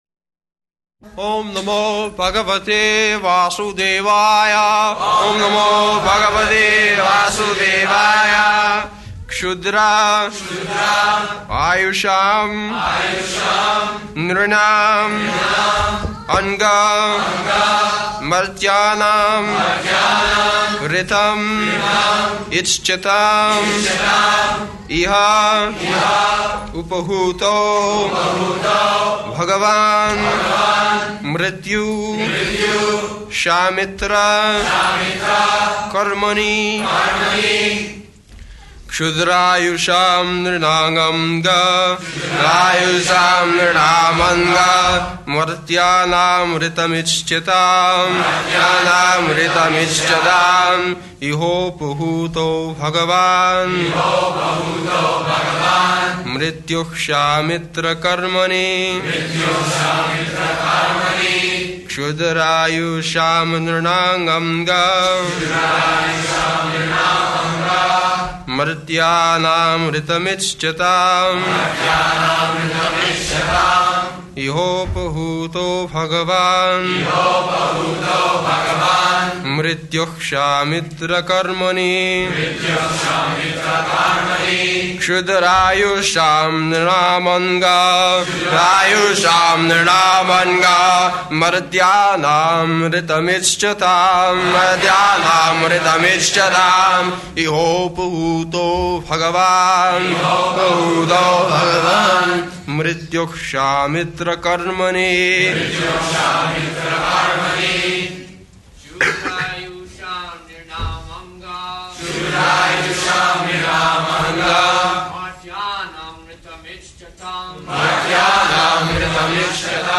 January 4th 1974 Location: Los Angeles Audio file
[devotees repeat]